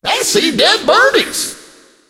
dynamike_ghost_start_vo_05.ogg